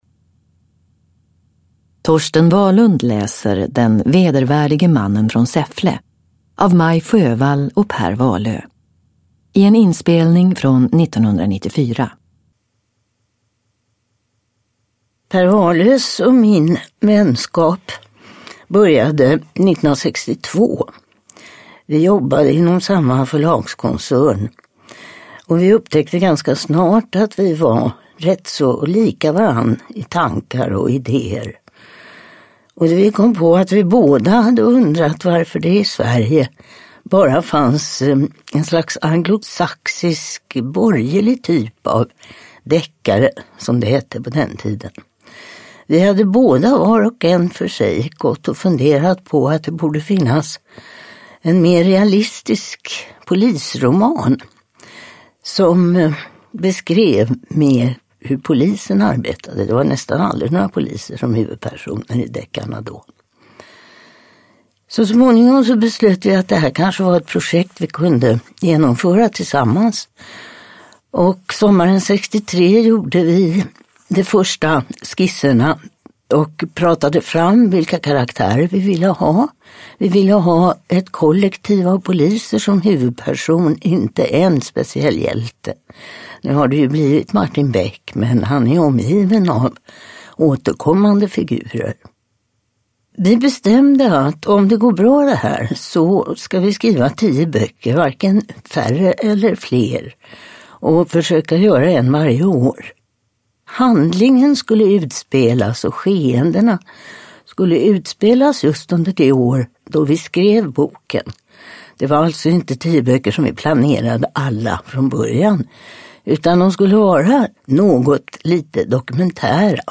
Den vedervärdige mannen från Säffle – Ljudbok – Laddas ner
Uppläsare: Torsten Wahlund